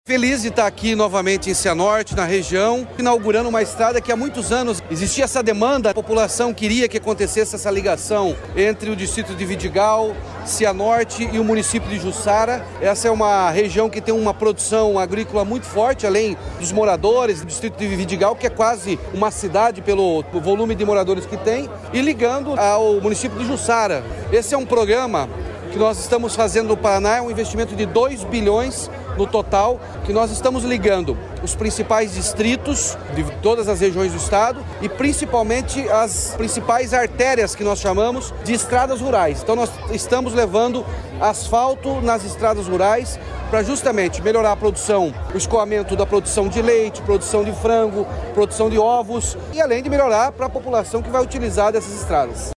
Sonora do governador Ratinho Junior sobre a pavimentação que liga distrito de Cianorte a Jussara